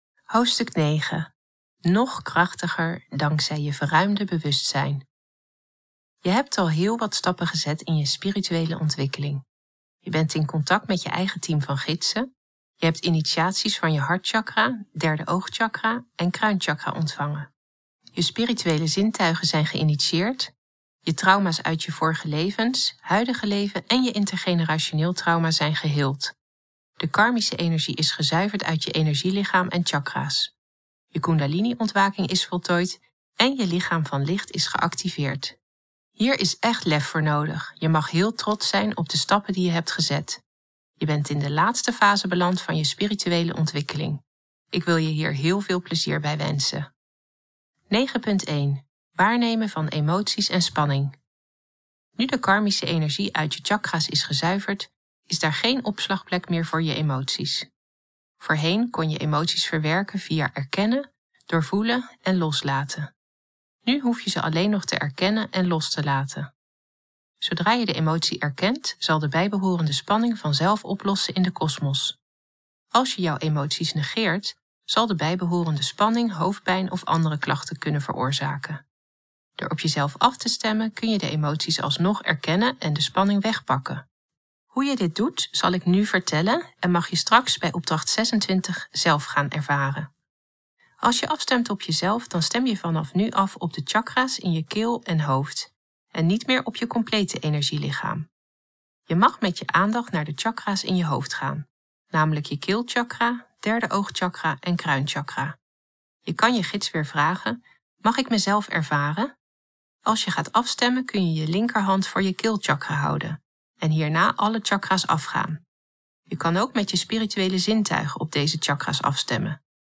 Het Luisterboek
Er wordt ook handpan muziek afgespeeld tussen de teksten.
De handpanmuziek vind ik ontzettend rustgevend
Oorstrelende handpanmuziek